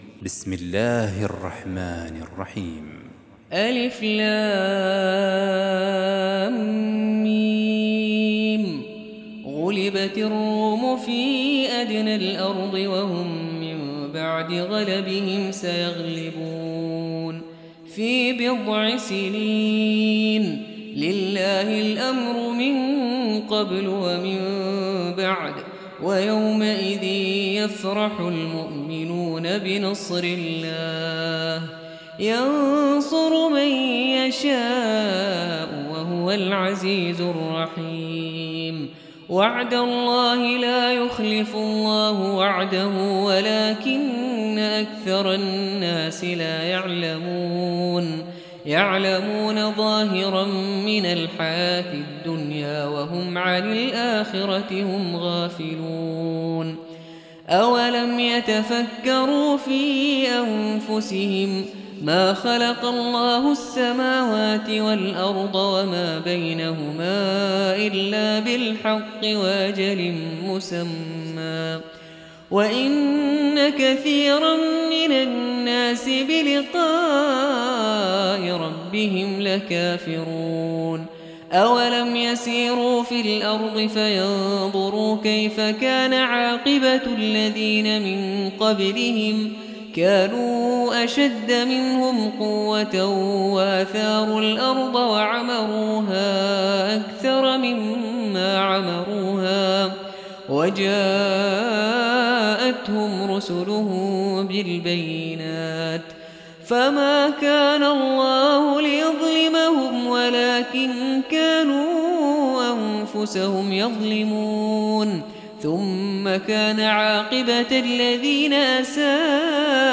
30. Surah Ar�R�m سورة الرّوم Audio Quran Tarteel Recitation
Surah Sequence تتابع السورة Download Surah حمّل السورة Reciting Murattalah Audio for 30.